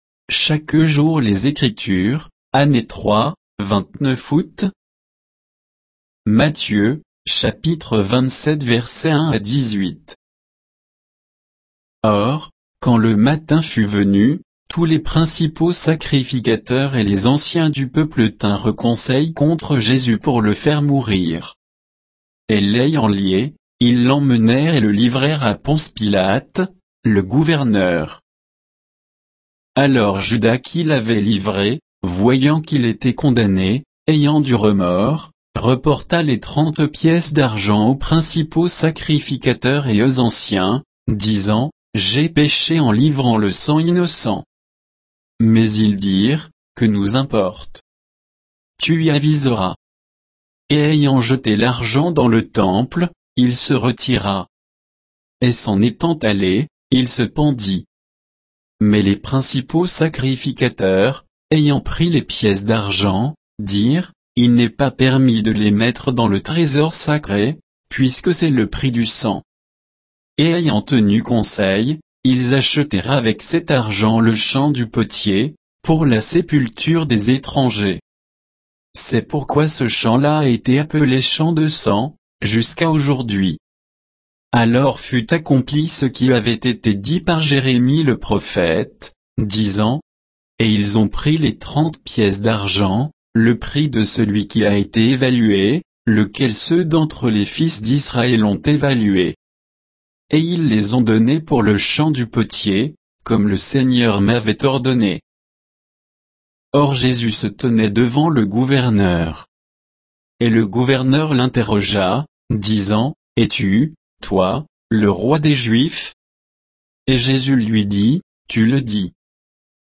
Méditation quoditienne de Chaque jour les Écritures sur Matthieu 27